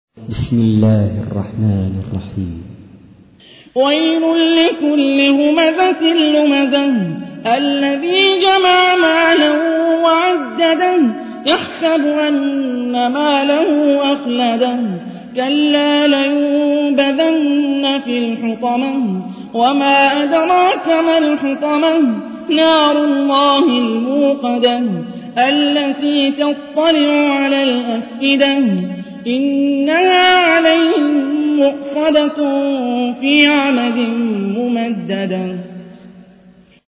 تحميل : 104. سورة الهمزة / القارئ هاني الرفاعي / القرآن الكريم / موقع يا حسين